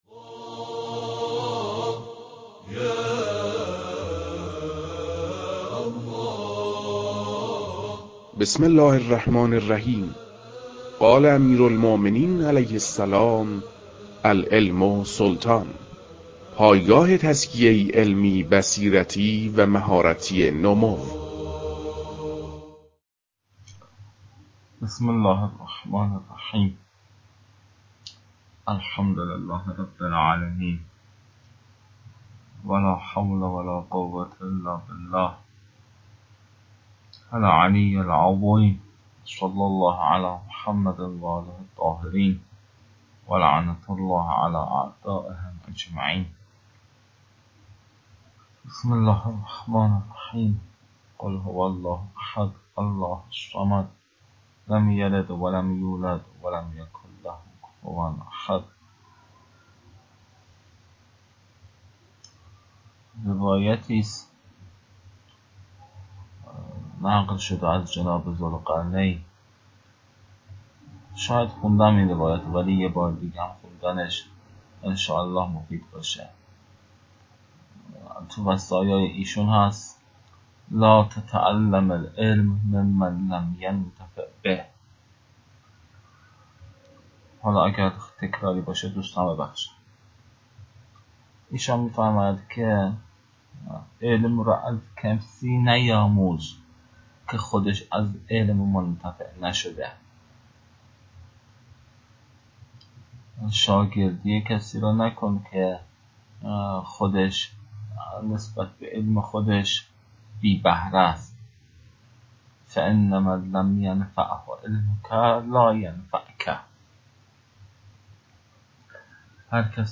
فایل های مربوط به تدریس مباحث تنبیهات معاطات